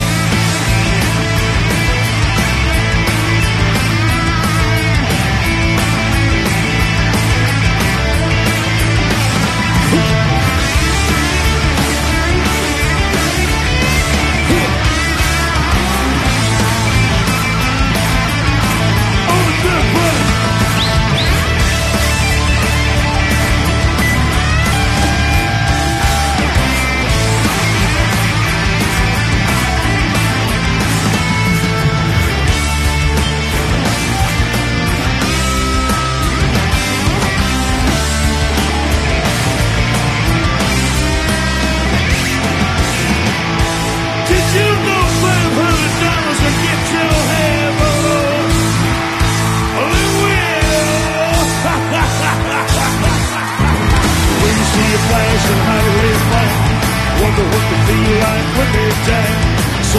Southern rock